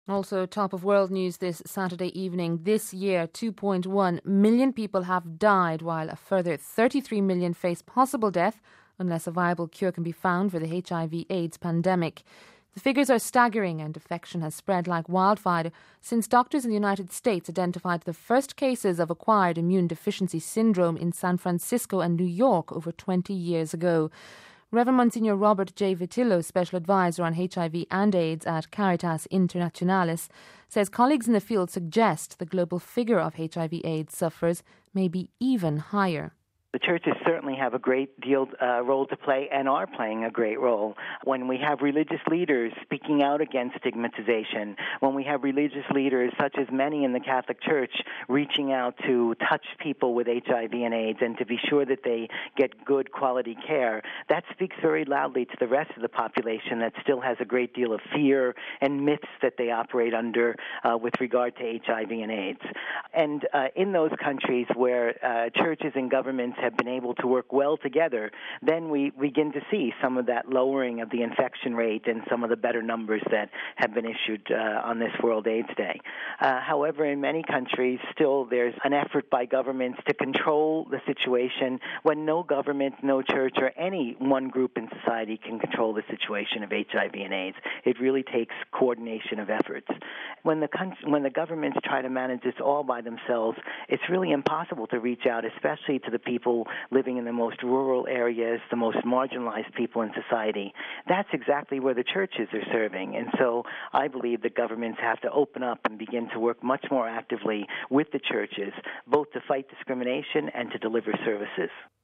Home Archivio 2007-12-01 18:38:48 Marking World Day Against AIDS (1 Dec 07-RV) As the annual World Day Against AIDS is marked today, 33 million people are facing possible death if a viable cure is not found. Here's our report...